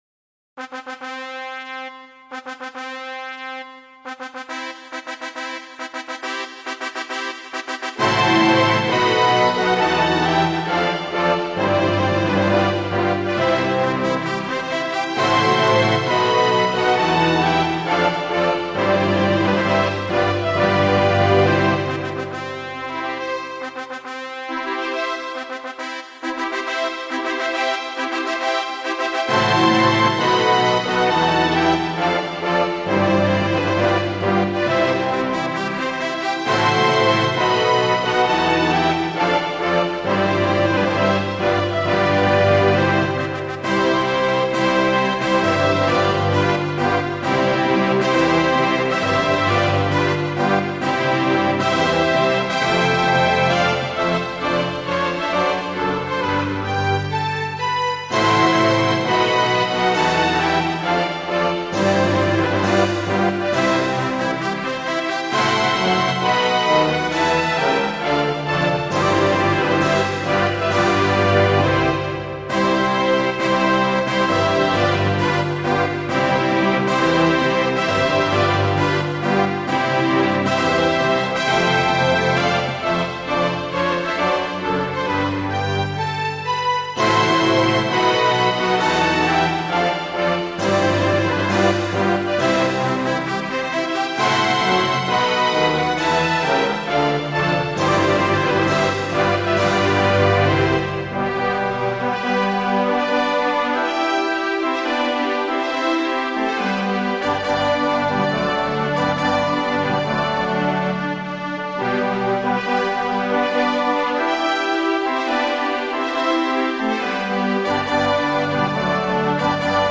＜注意＞ＭＰ３データはＳＣ８８５０で演奏したものです